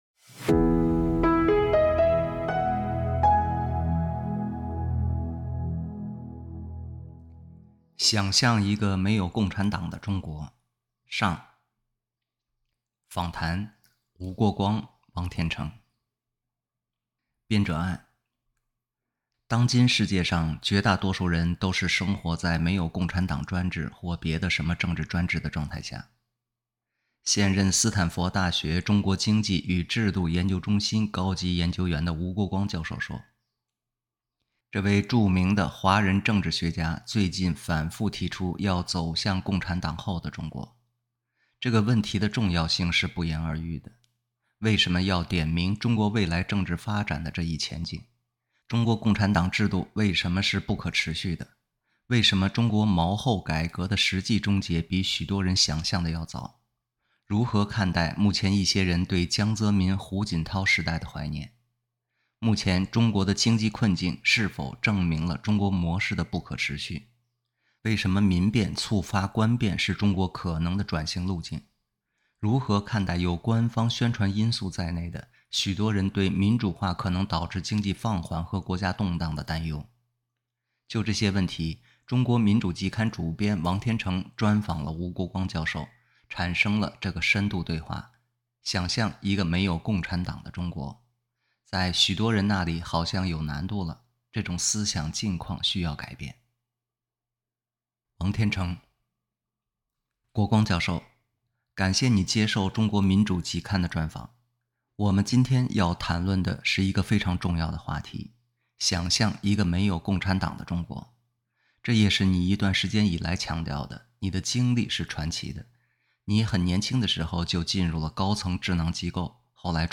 产生了这个深度对话